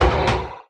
Minecraft Version Minecraft Version 1.21.5 Latest Release | Latest Snapshot 1.21.5 / assets / minecraft / sounds / mob / irongolem / hit4.ogg Compare With Compare With Latest Release | Latest Snapshot
hit4.ogg